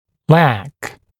[læk][лэк]недостаток, недостаточное количество, нехватка; не хватать, недоставать